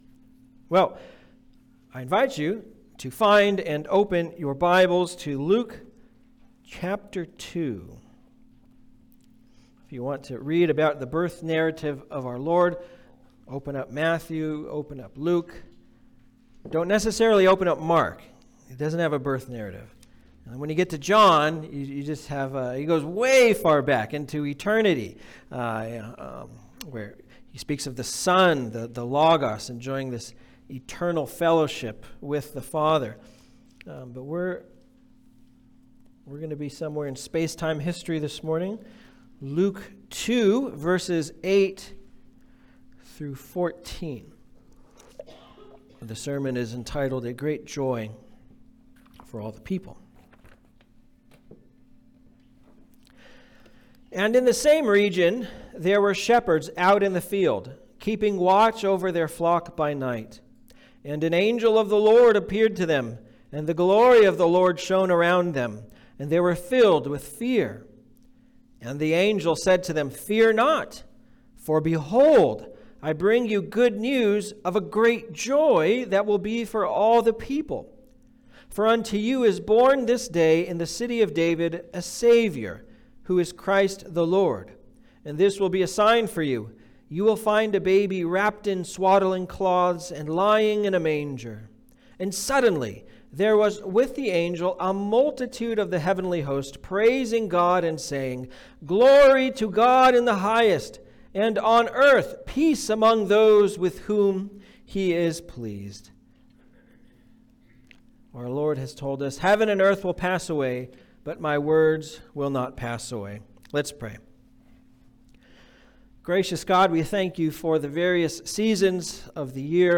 Passage: Luke 2:8-14 Service Type: Sunday Service